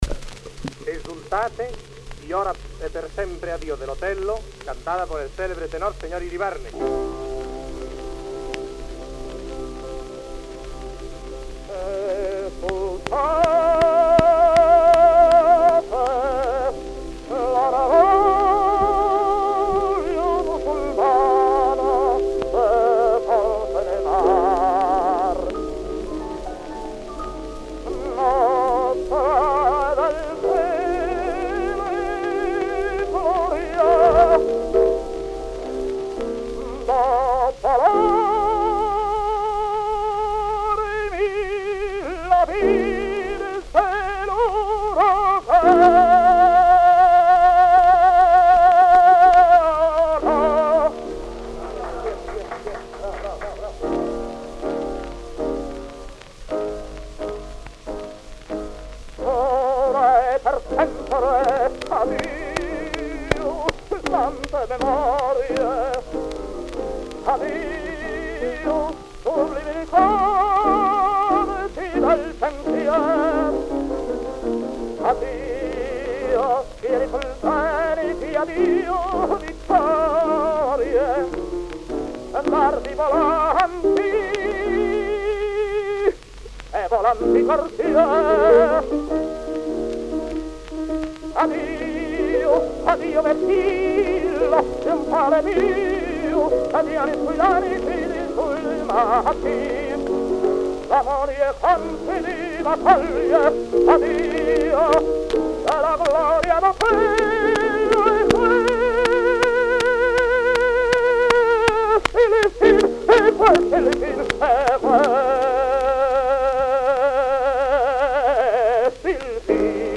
more than 350 unique cylinders, all preserved in good shape and now in the collection of the Library of Catalonia.
and his voice was a wreck even by then; but his musicality is remarkable.